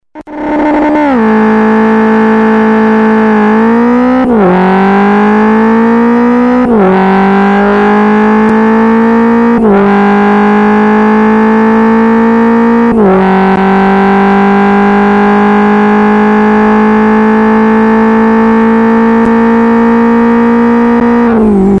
all i hear from the previews on the FZ's are 100% clippage
They sound like an old 80s racing game.
However the majority of the sounds are aggressive / meaty due to the aggressive clipping.